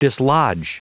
Prononciation du mot dislodge en anglais (fichier audio)
Prononciation du mot : dislodge